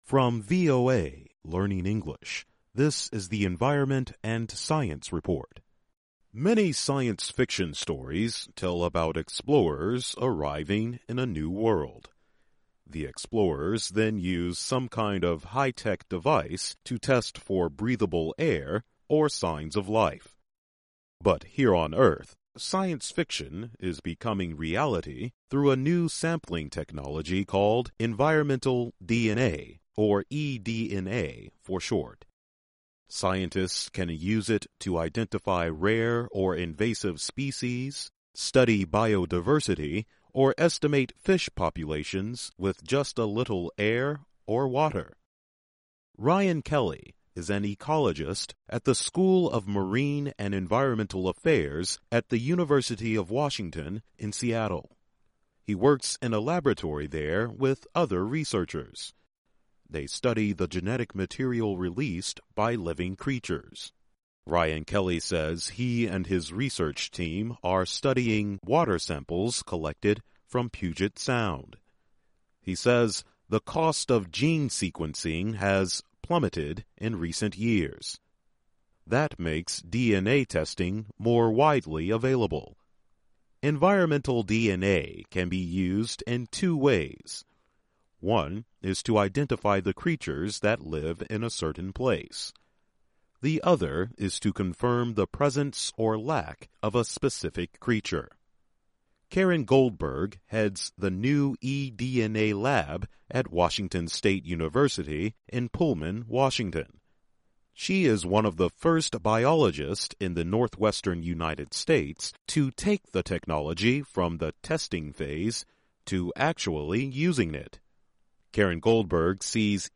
Learning English as you listen to a weekly show about the environment, science, farming, food security, gardening and other subjects. Our daily stories are written at the intermediate and upper-beginner level and are read one-third slower than regular VOA English.